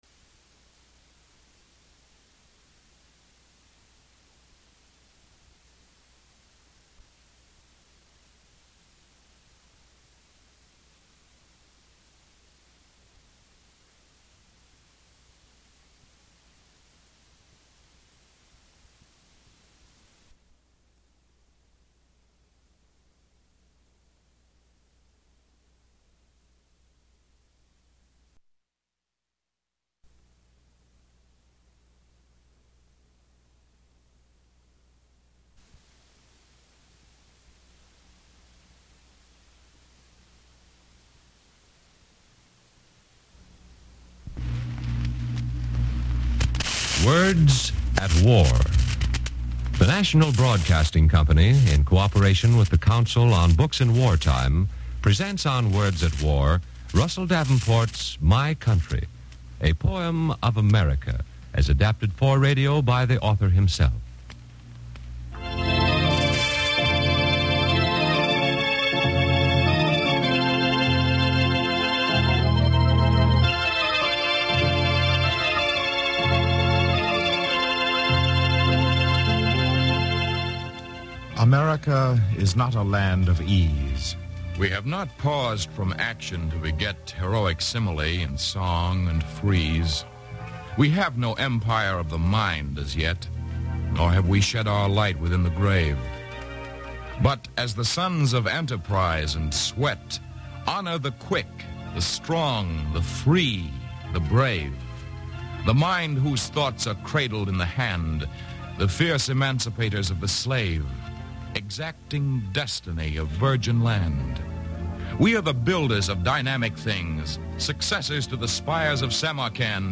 The entire program is performed in verse. A moving, patriotic work...a sound portrait in words.